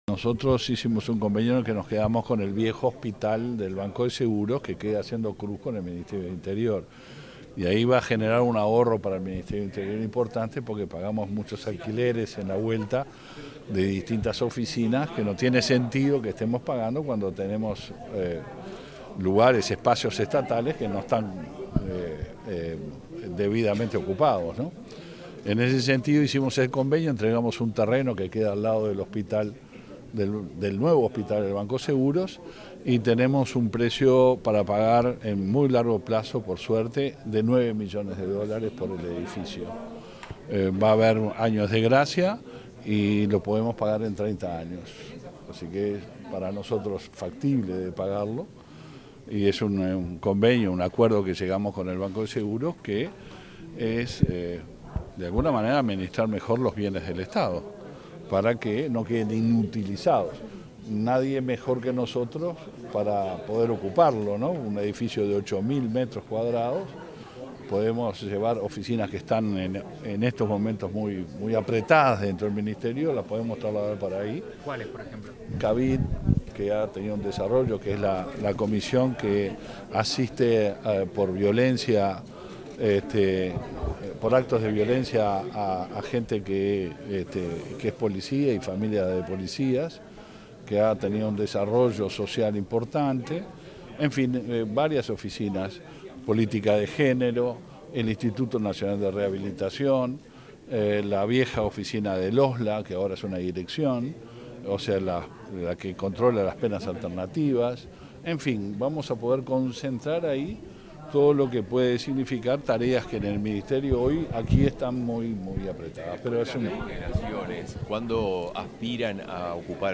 Declaraciones del ministro del Interior, Luis Alberto Heber
Declaraciones del ministro del Interior, Luis Alberto Heber 17/02/2023 Compartir Facebook X Copiar enlace WhatsApp LinkedIn Tras la firma de un convenio con el Banco de Seguros del Estado (BSE), este 17 de febrero, el ministro del Interior, Luis Alberto Heber, realizó declaraciones a la prensa.